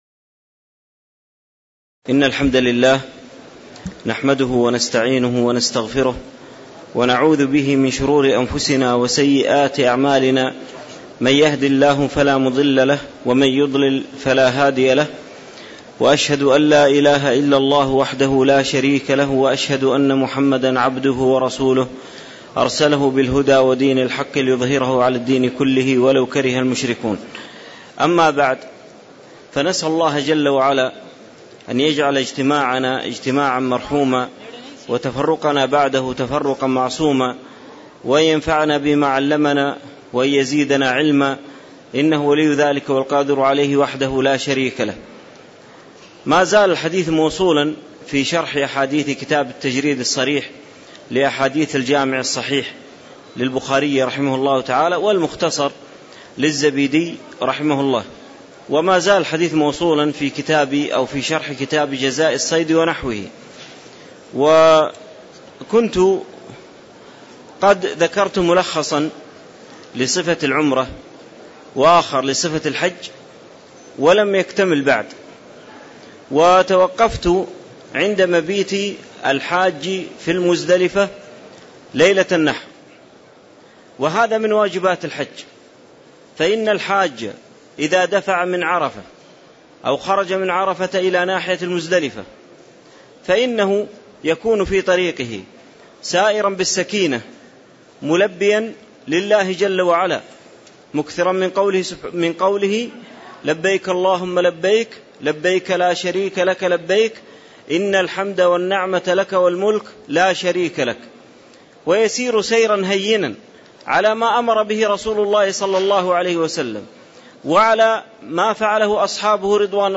تاريخ النشر ٢٦ ذو القعدة ١٤٣٧ هـ المكان: المسجد النبوي الشيخ